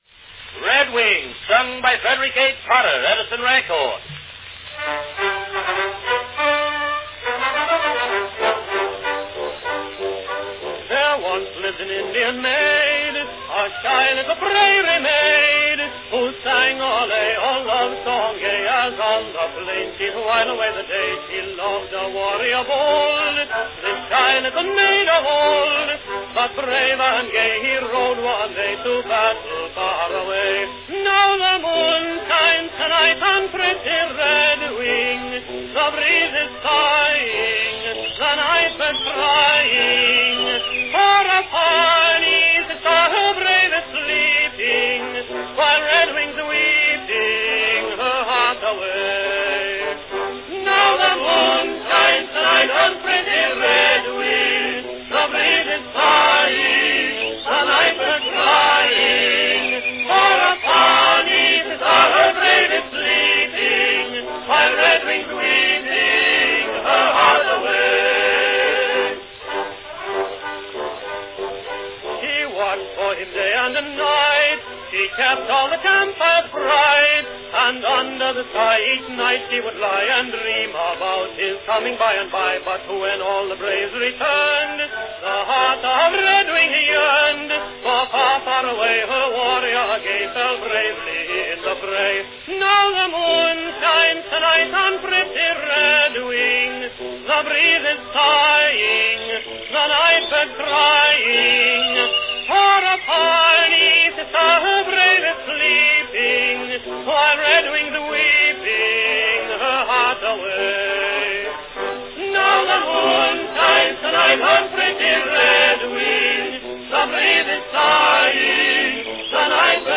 Category Tenor solo
two-minute wax cylinders
is a pretty little song, with orchestral accompaniment.